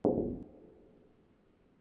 FootstepHandlerGlass1.wav